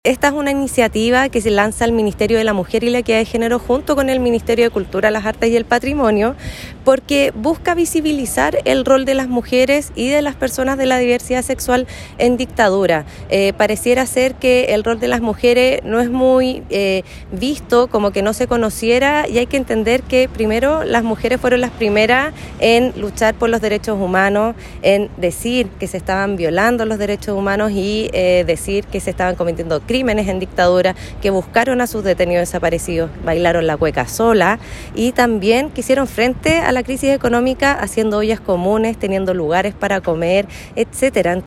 Esta es una iniciativa liderada por el Ministerio de las Culturas, las Artes y el Patrimonio junto al Ministerio de la Mujer y la Equidad de Género, buscando dar un reconocimiento a todas las mujeres que sobrevivieron la dictadura militar y quienes comenzaron denunciando las violaciones a los derechos humanos buscando a sus familiares, como lo explicó Macarena Gré, Seremi de la Mujer y Equidad de Género en la Región de Los Lagos.